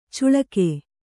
♪ cuḷake